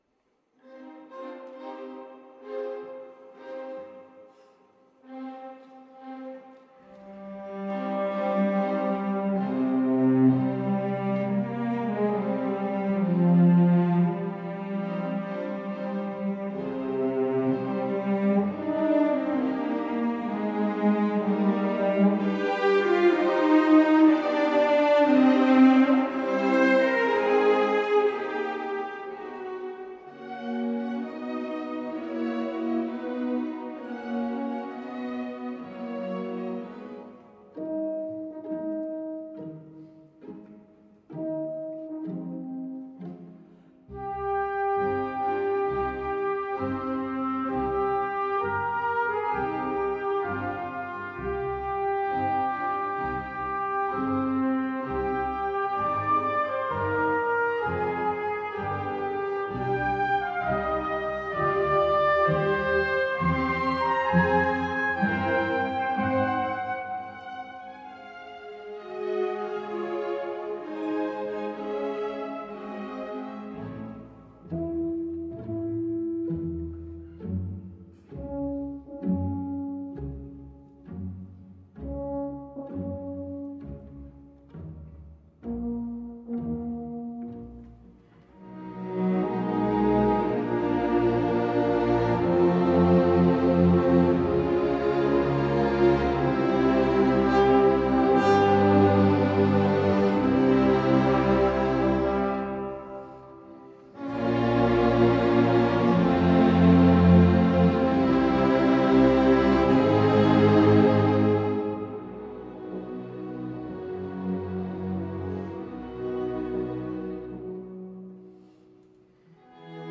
Here is a performance of the Bruckner Symphony No. 4.